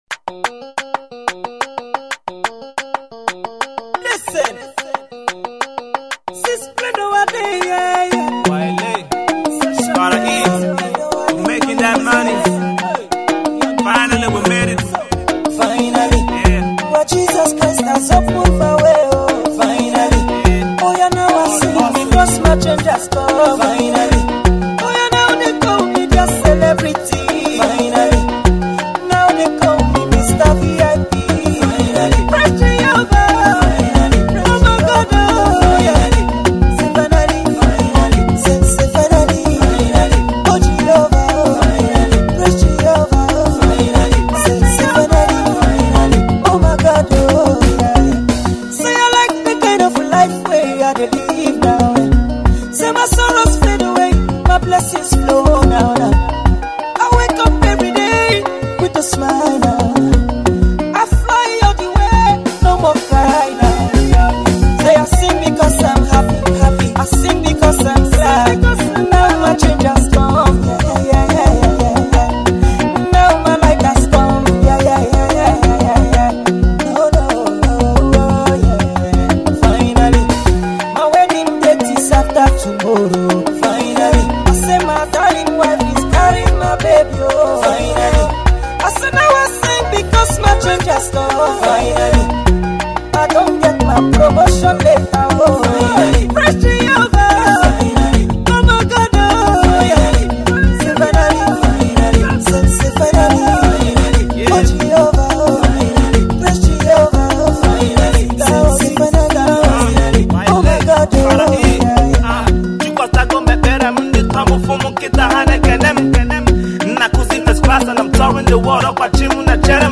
The song is more or less a Prayer, which was well produced